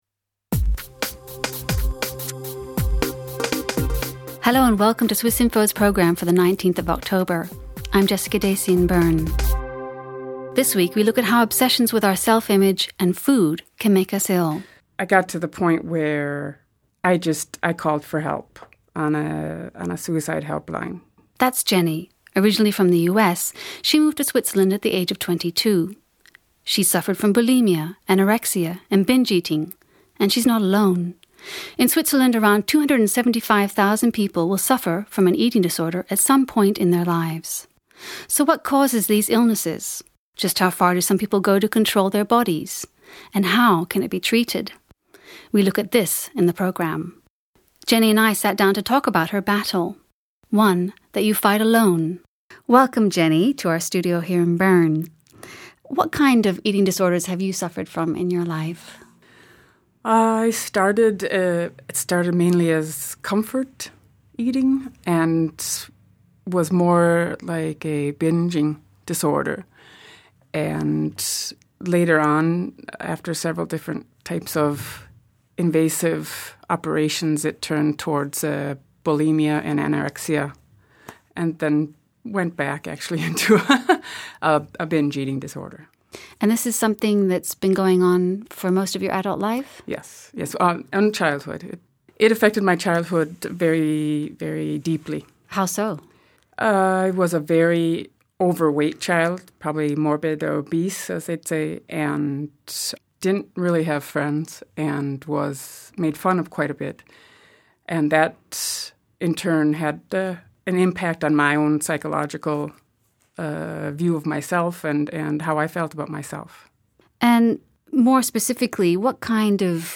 Just how far do some people go to control their body weight and why? Around 275,000 people in Switzerland will suffer from eating disorder during their lifetime. We speak to a former anorexic and a life-long food obsessive.